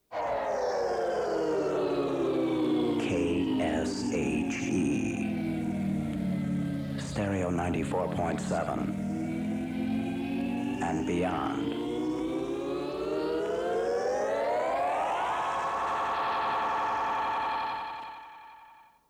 And Beyond promo · St. Louis Media History Archive